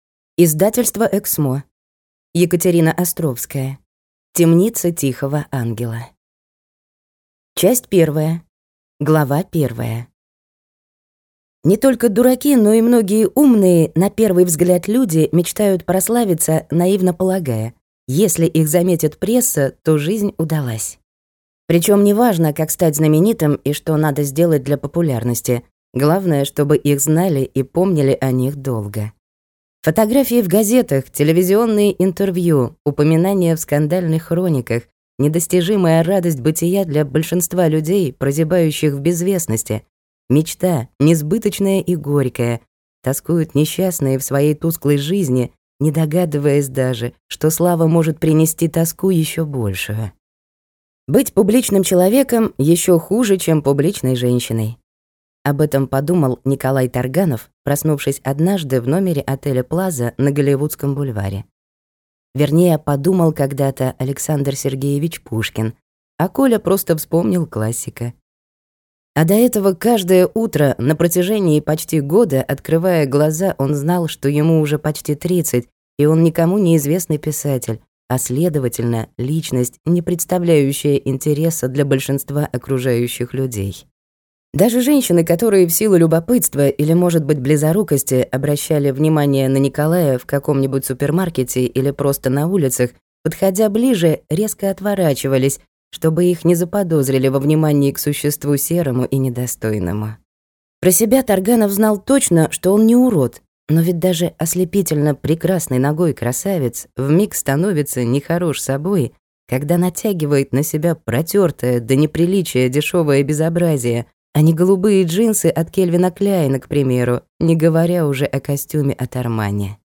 Аудиокнига Темница тихого ангела | Библиотека аудиокниг